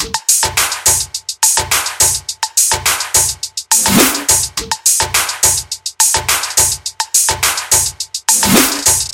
105 bpm th drum loop no kick
描述：这些循环是在FL Studio 12中创建的，分为4个部分：踢腿、拍子、hihat、perc和snare。我认为，这些循环可以用于热带屋和舞厅。
Tag: 105 bpm House Loops Drum Loops 1.54 MB wav Key : Unknown